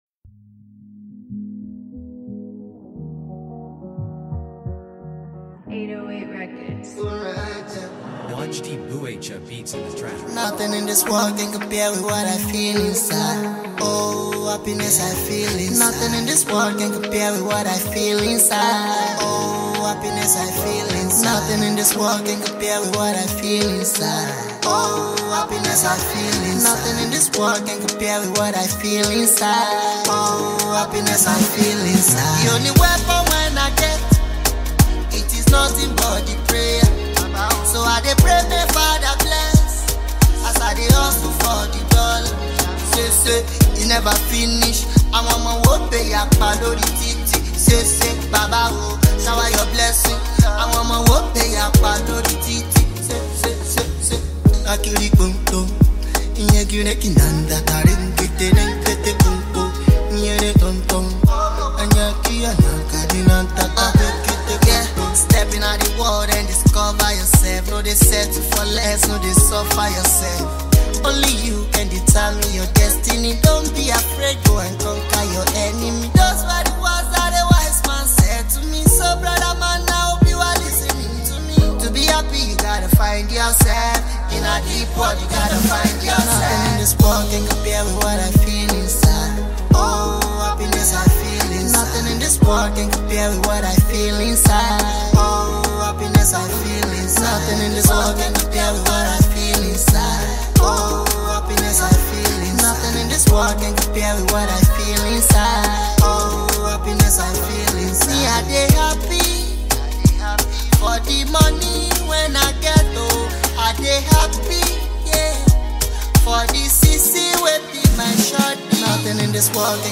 banging single
lovely anthem